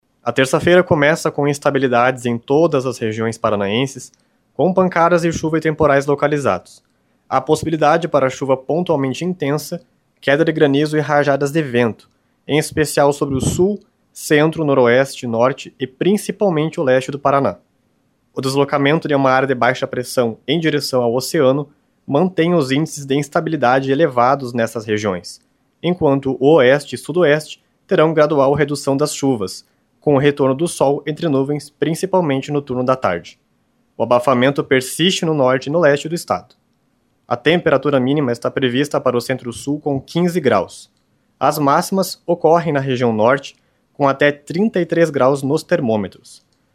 Ouça a previsão em detalhes com o meteorologista do Simepar